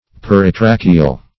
Peritracheal \Per`i*tra"che*al\, a.